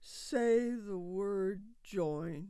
HAS-Corpus / Audio_Dataset /sad_emotion /1591_SAD.wav
1591_SAD.wav